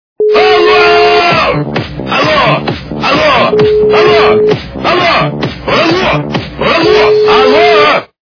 При прослушивании Сумасшедший телефон - Алло-Алло-алллллоооо!!! качество понижено и присутствуют гудки.